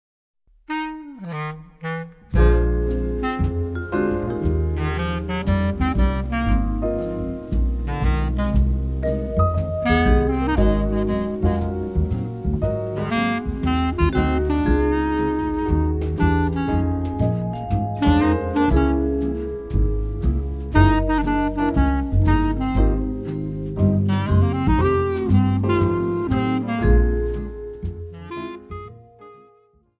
今回もスタンダードナンバー、賛美歌で構成されバラードソング中心の選曲。
アコーディオンとギターの音色も魅力的なCDです。
Vibraphone
Piano
Bass
Drums
Accordion
Guitar